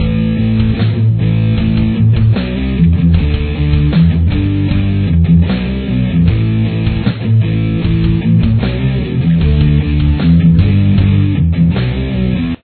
Chorus
Guitar 1
Guitar 2
Here’s what both guitars sound like together: